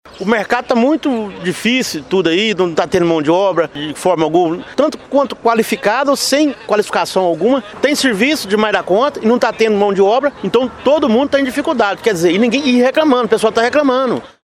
Funcionário